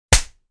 На этой странице представлены звуки подзатыльника – резкие, неожиданные аудиоэффекты.
Звук подзатыльника: удар ладонью по голове сзади